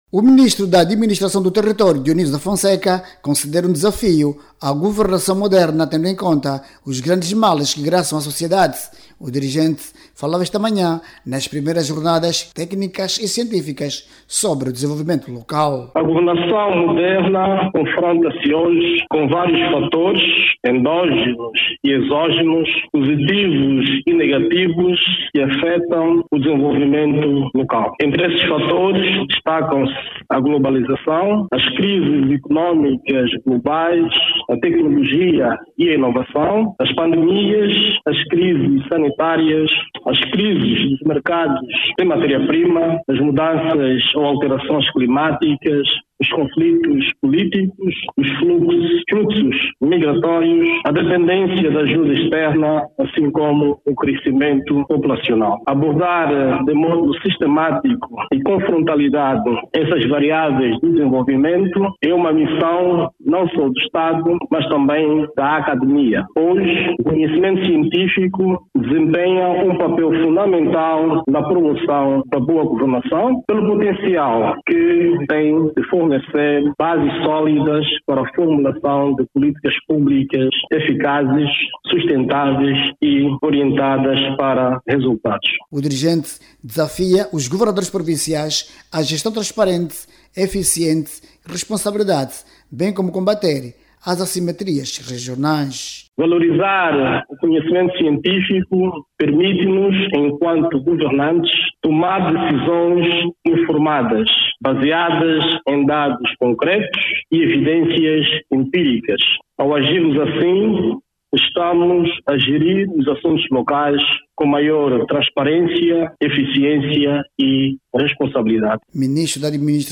O Ministro da Administração do Território, Dionísio da Fonseca reafirma o compromisso do executivo, continuar a trabalhar na solução dos principais problemas da população. Discursando hoje, quarta-feira(06), em Benguela na abertura das jornadas técnicas e científicas sobre o desenvolvimento local, o ministro Dionísio da Fonseca, falou sobre os desafios da governação, onde a academia é chamada a dar o seu melhor.